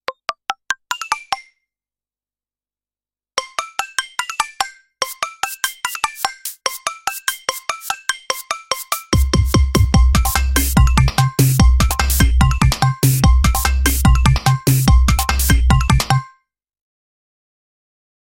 nokia-lumia-chipper_24527.mp3